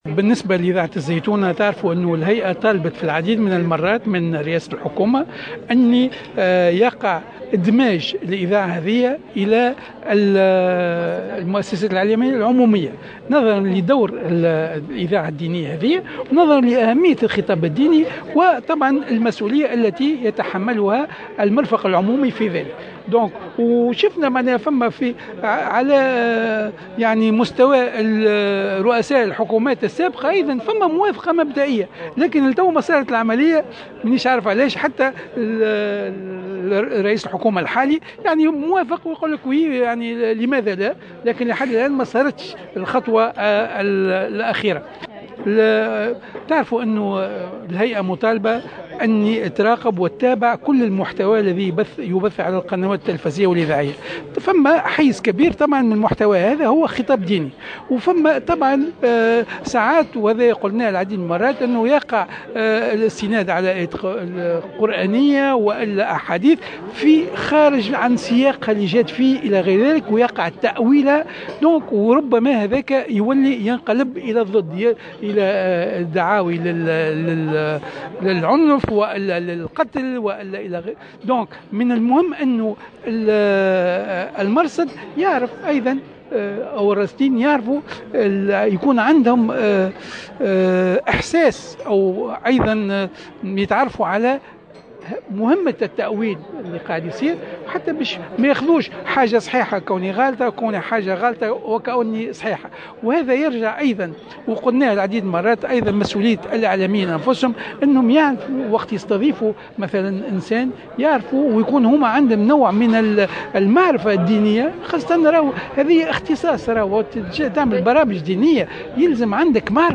وشدد اللجمي في تصريح لمراسلة الجوهرة أف أم، على هامش دورة تكوينية حول الخطاب الديني لفائدة مراقبي وحدة الرصد بالهايكا بالمركز الإفريقي لتدريب الصحفيين والإتصاليين، اليوم الأربعاء، على أهمية دراية الإعلاميين بالخطاب الديني والتخصص في هذا الشأن مما يمكنهم من إدارة الحوارات دون السقوط في فخ التأويلات الخاطئة، أو تمرير المضامين الخاطئة التي قد يبثها الضيوف ممن يقدمون أنفسهم على أنهم خطباء.